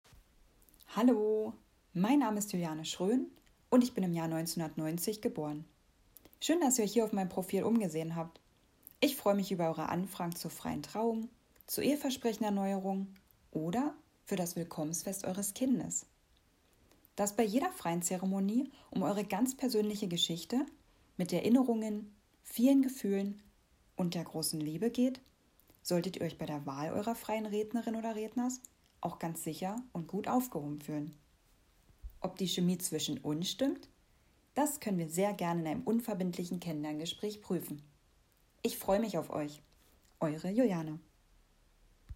Stimmprobe